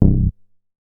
MoogEatBass A.WAV